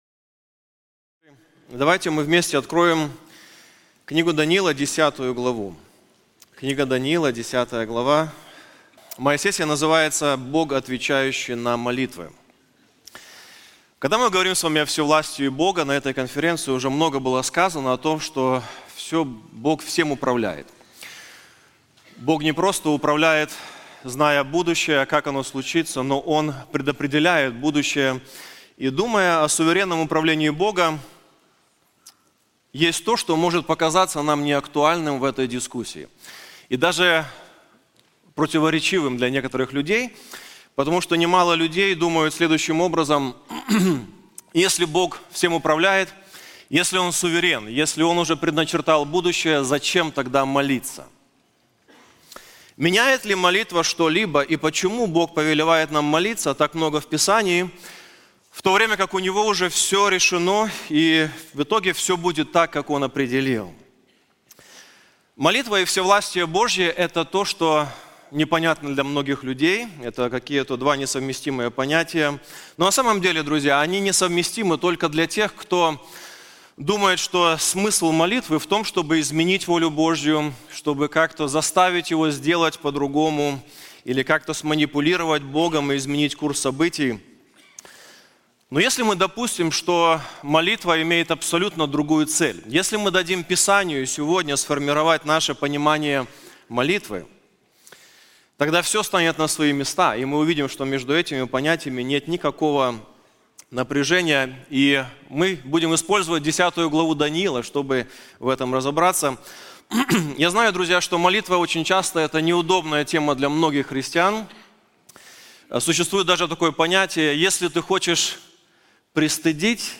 На конференции "Владыка истории" мы рассмотрим книгу пророка Даниила, каждая глава которой ясно показывает, что история находится в руках Бога.